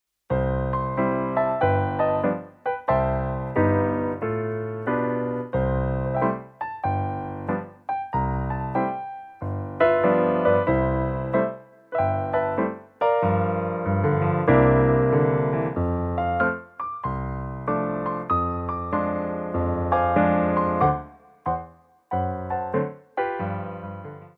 Piano Arrangements
SLOW TEMPO